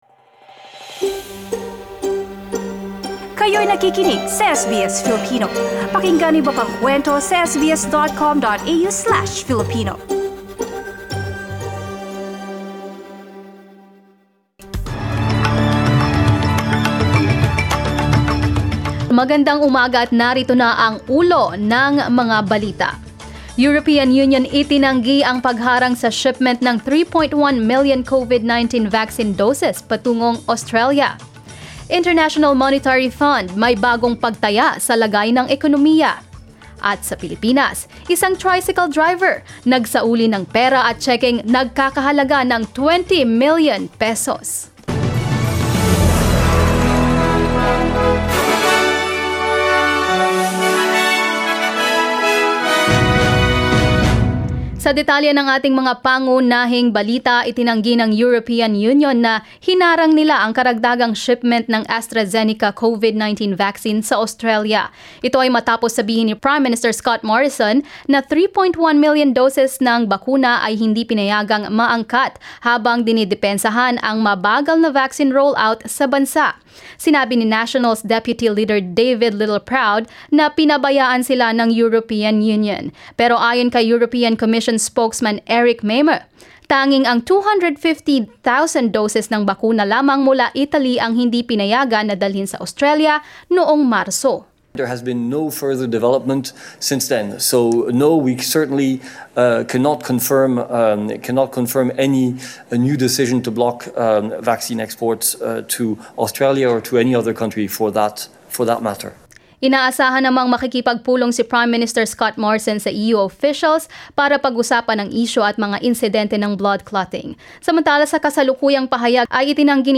Mga balita ngayong ika-7 ng Abril